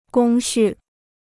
工序 (gōng xù): ขั้นตอนการผลิต; กระบวนการทำงาน.